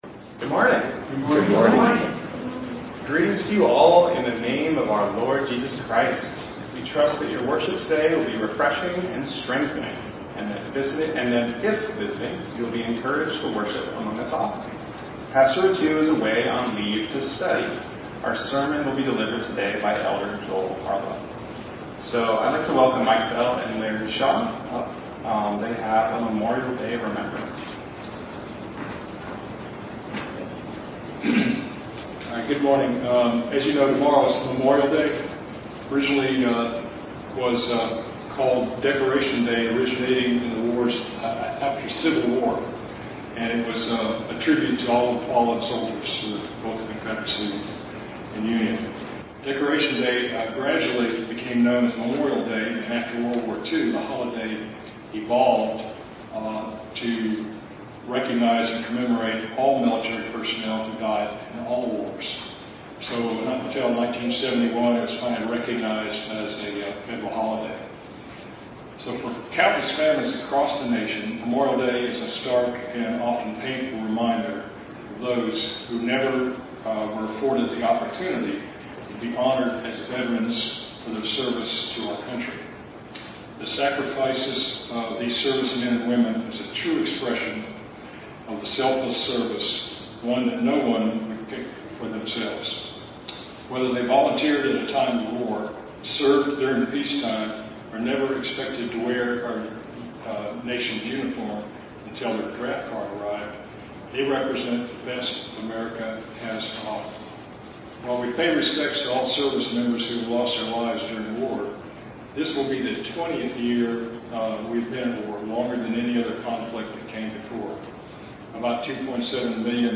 The services we post here were preached the previous week.